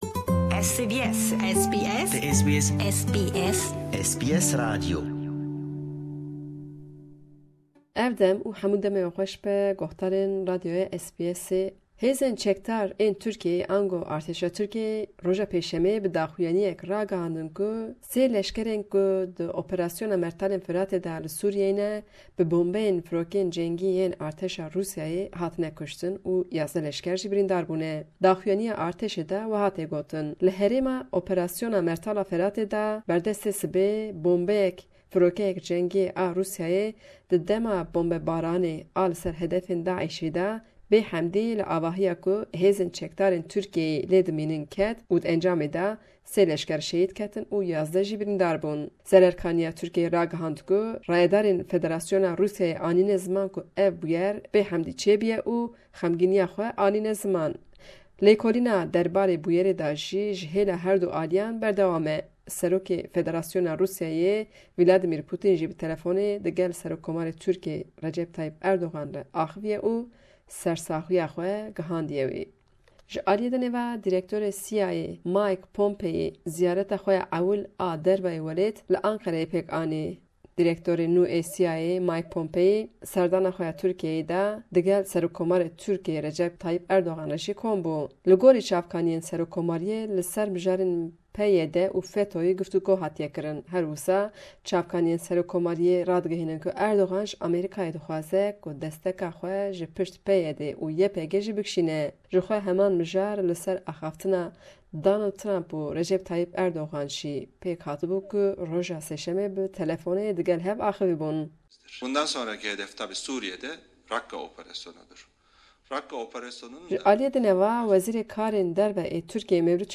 Raporta peyamnêra me